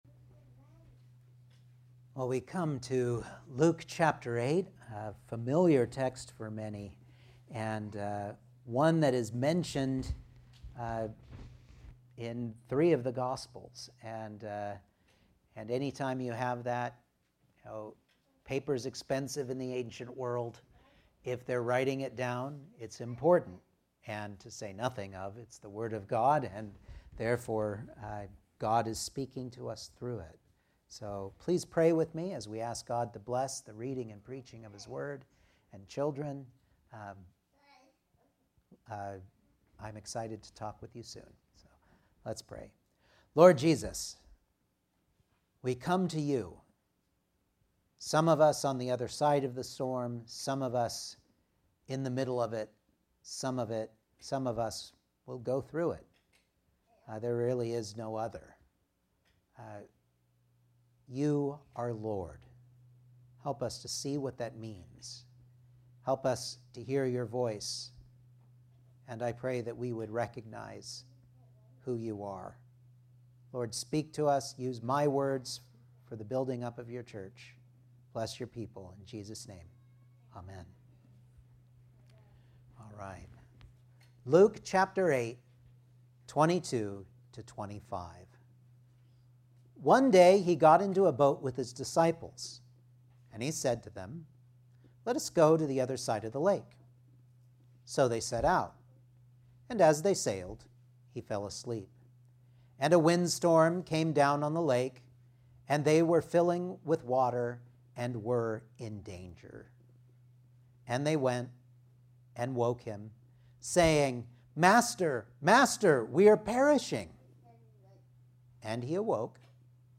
Luke 8:22-25 Service Type: Sunday Morning Outline